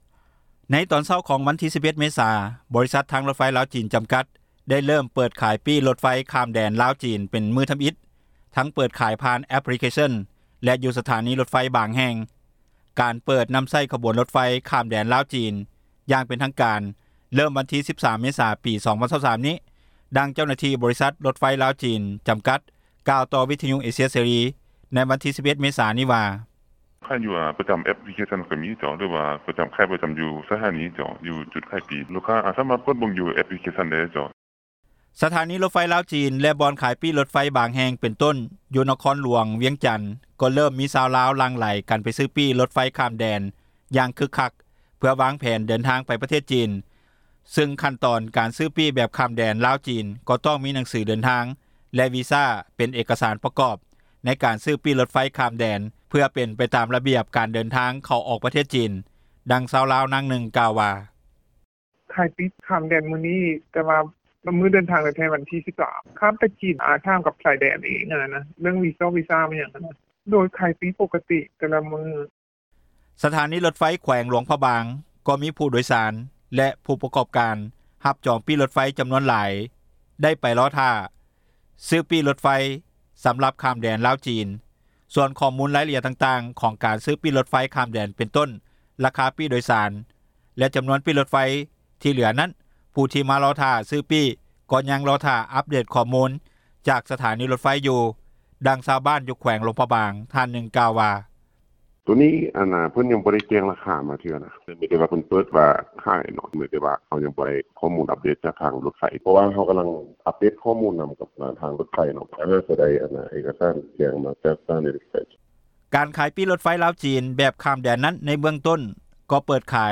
ດັ່ງເຈົ້າໜ້າທີ່ ບໍຣິສັດ ທາງຣົຖໄຟ ລາວ-ຈີນ ຈຳກັດກ່າວຕໍ່ວິທຍຸເອເຊັຽເສຣີ ໃນວັນທີ 11 ເມສານີ້ວ່າ: